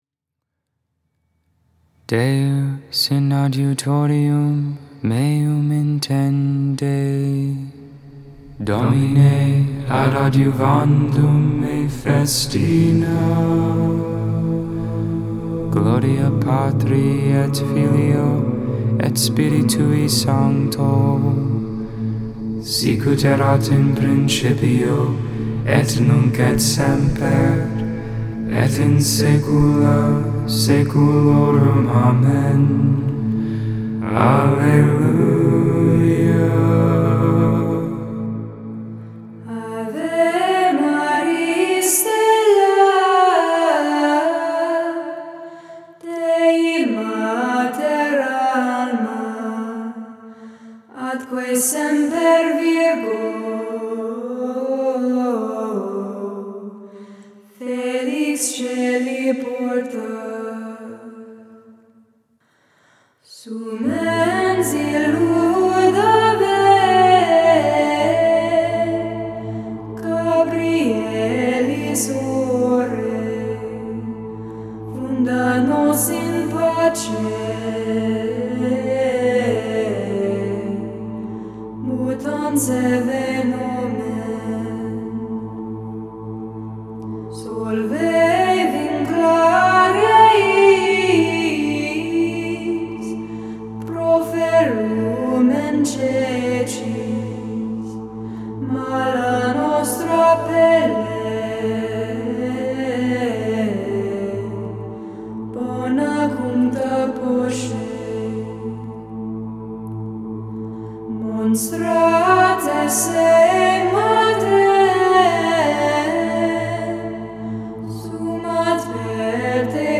Vespers I, Evening Prayer for Saturday of the 3rd week of Eastertide.